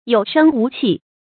有聲無氣 注音： ㄧㄡˇ ㄕㄥ ㄨˊ ㄑㄧˋ 讀音讀法： 意思解釋： ①形容人氣息奄奄。②形容說話無精打采。